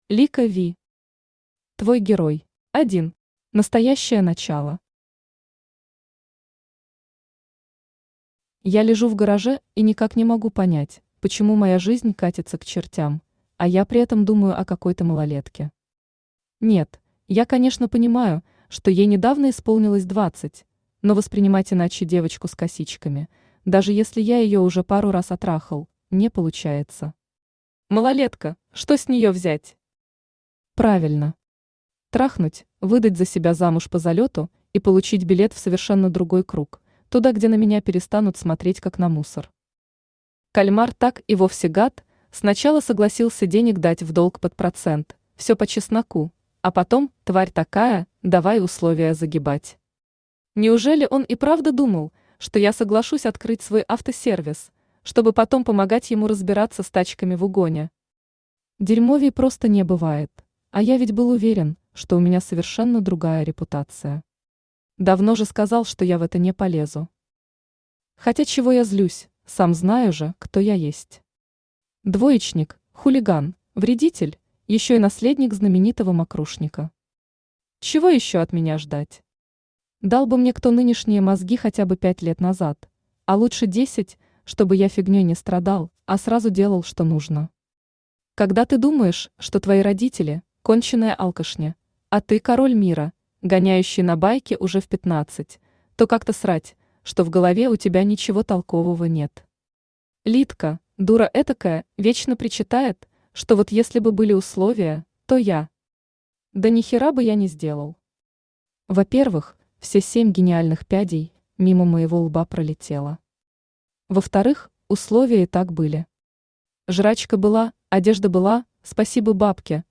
Аудиокнига Твой герой | Библиотека аудиокниг
Aудиокнига Твой герой Автор Лика Ви Читает аудиокнигу Авточтец ЛитРес.